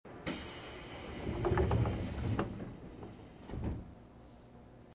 「カラカラ」「カタカタ」言うのが特徴。　ドアレールとの隙間が大きいのか、走行中まで異音がする。